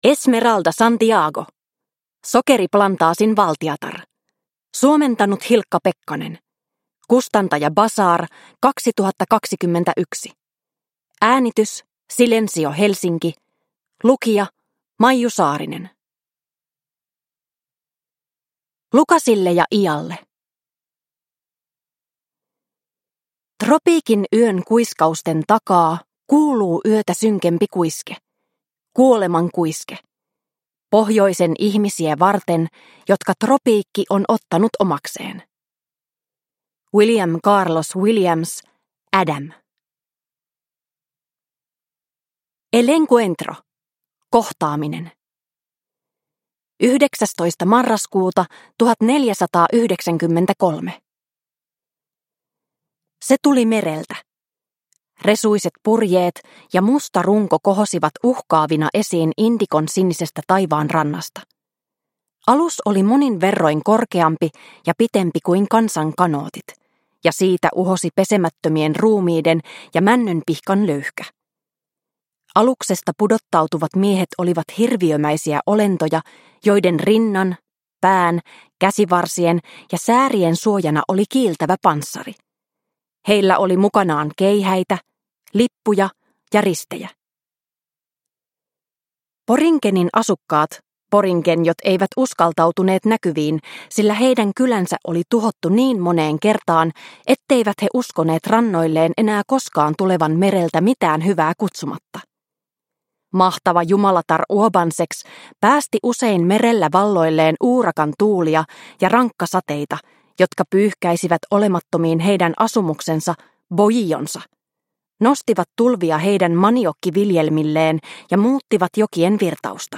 Sokeriplantaasin valtiatar – Ljudbok – Laddas ner